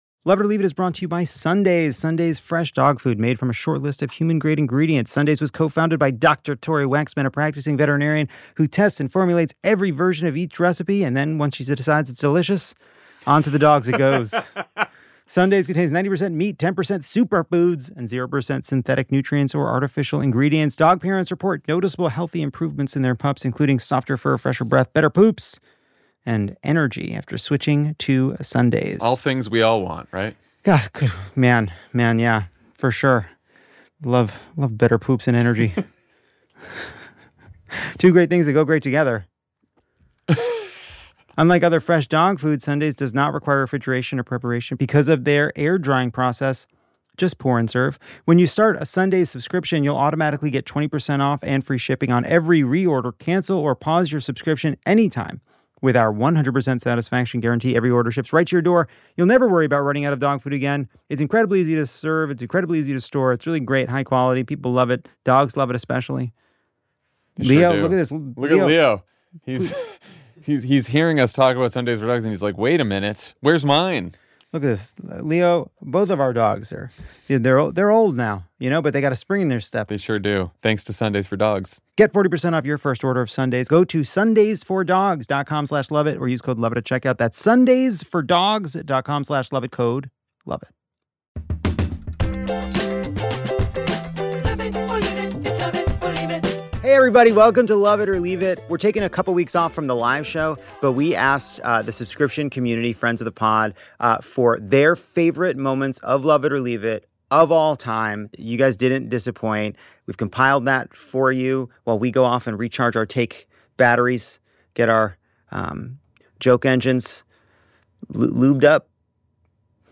In honor of our independence and courtesy of our beloved Friends of the Pod, we present some of the best rants from guests who went off louder than a firework ever could. Lettuce between cheese and meat. Turning 40 with drunken grace.